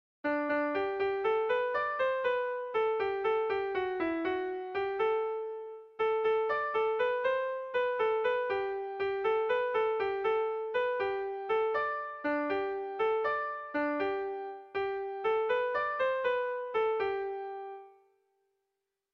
Bertso melodies - View details   To know more about this section
Kontakizunezkoa
Aulesti < Lea-Artibai < Bizkaia < Basque Country
Lauko handia (hg) / Bi puntuko handia (ip)
ABD